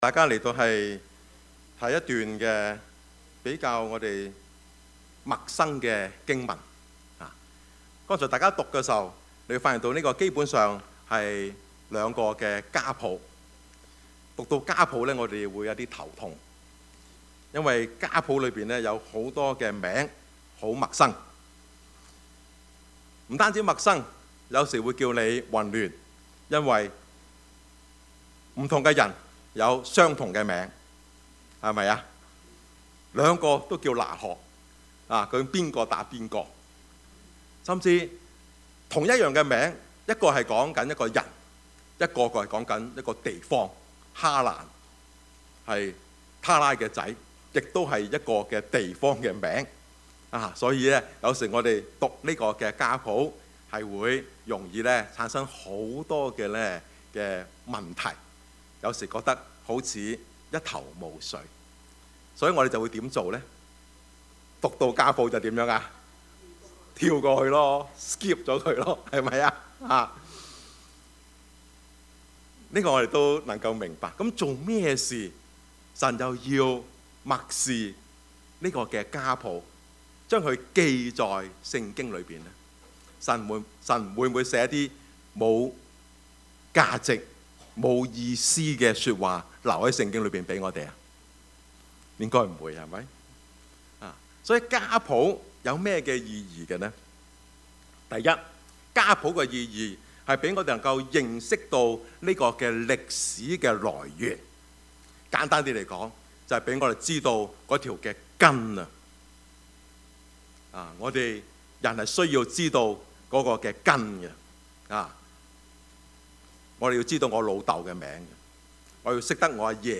Passage: 創 世 記 11:10-32 Service Type: 主日崇拜
Topics: 主日證道 « 統一與分散 異鄉鄰舍情 »